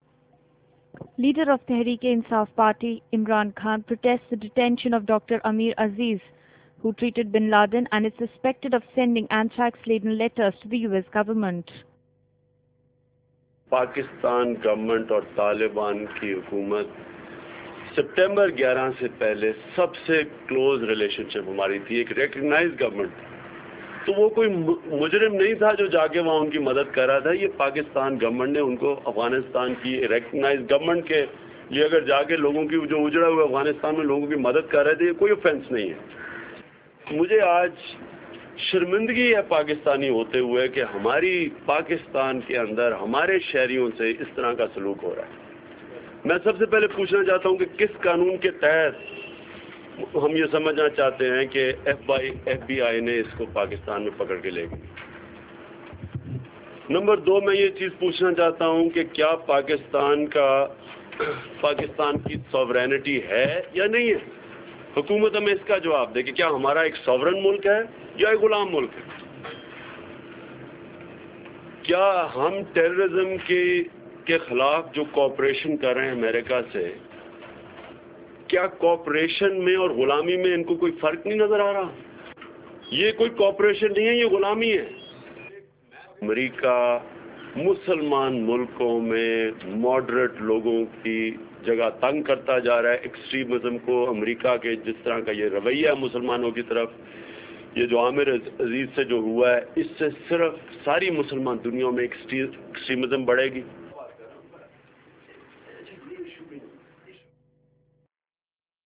In video: Leader of Teherik-e-Insaf party Imran Khan protests the detention of a Pakistani physician, who treated bin Laden and is suspected of sending Anthrax laden letters to the US government.